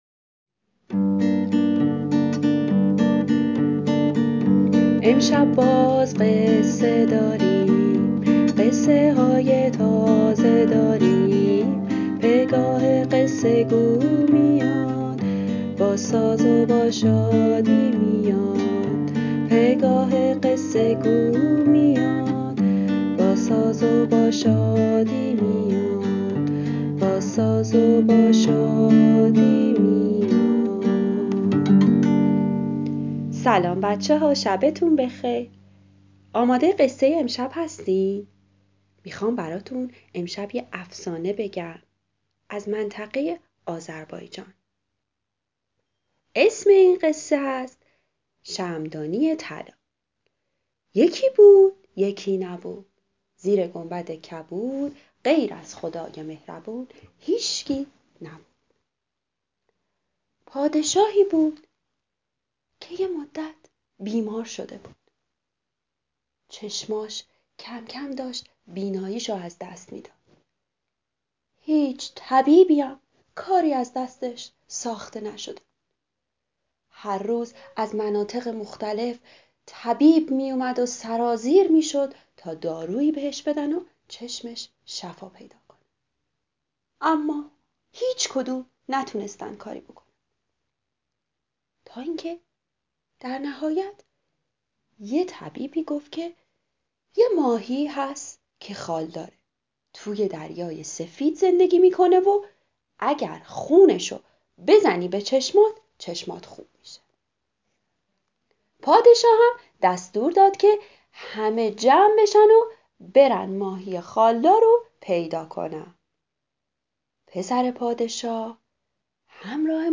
قصه صوتی کودکان دیدگاه شما 1,478 بازدید